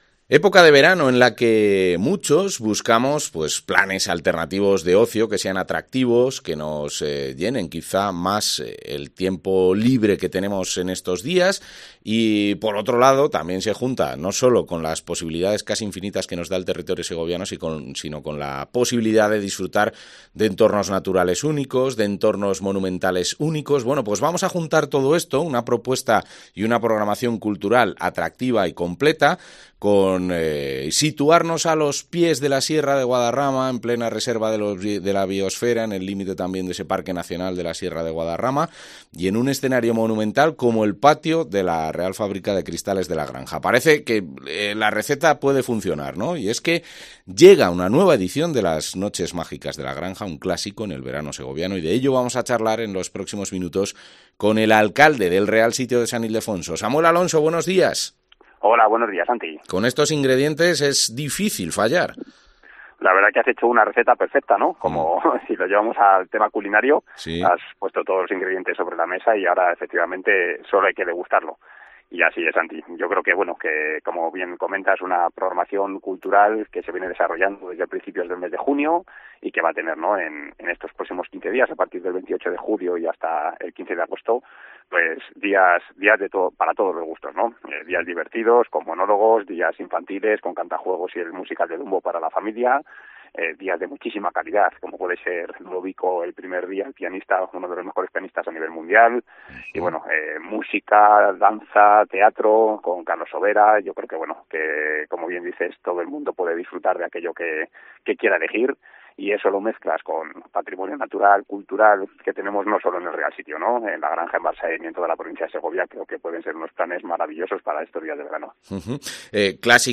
Samuel Alonso, alcalde del Real Sitio de San Ildefonso